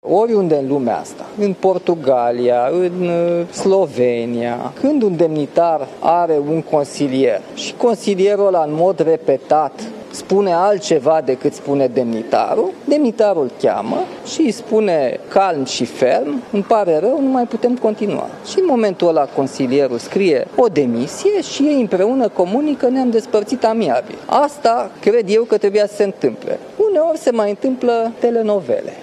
Nicușor Dan a fost întrebat de jurnaliști și dacă o eventuală revenire a lui Ludovic Orban în PNL ar fi considerată o trădare
21nov-15-Nicusor-Dan-reactie-demitere-Orban-.mp3